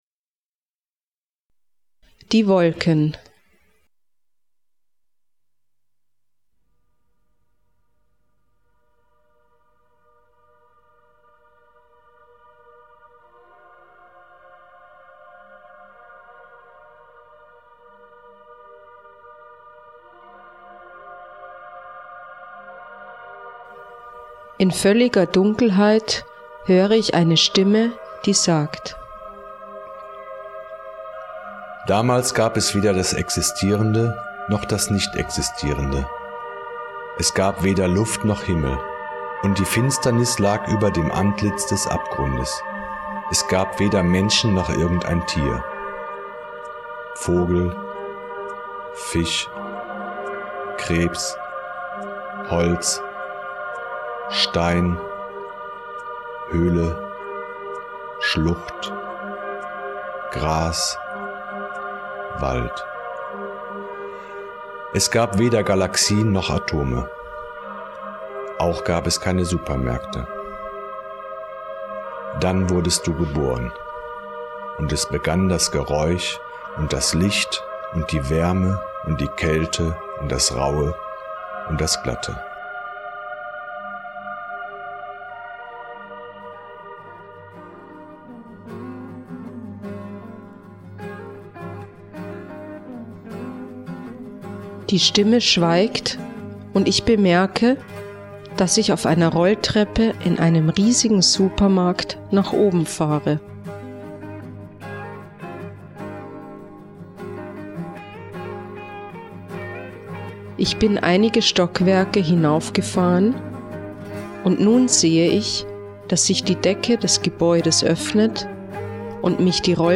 Geleitete Erfahrungen - Die Wolken - Gemeinschaften von Silos Botschaft